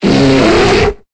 Cri de Pandarbare dans Pokémon Épée et Bouclier.